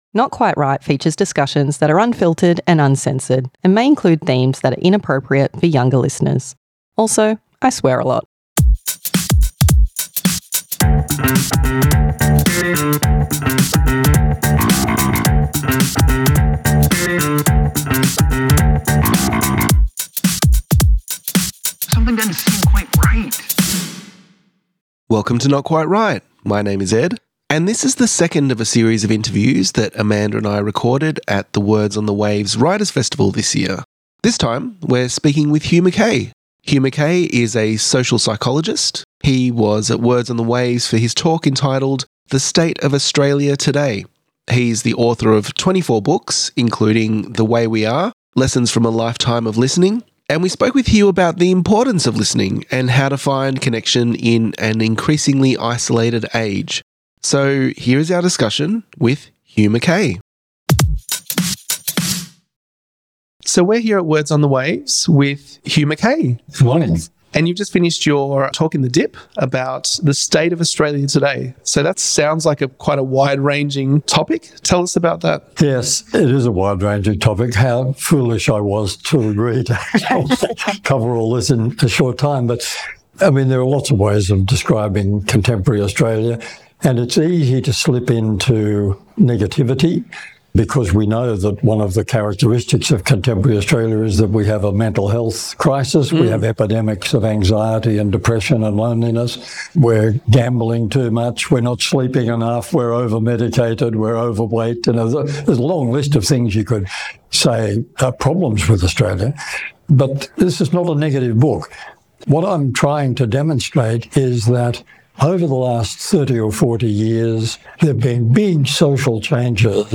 Interview with Hugh Mackay at Words on the Waves 2025 - Not Quite Write Podcast
interview-with-hugh-mackay-at-words-on-the-waves-2025.mp3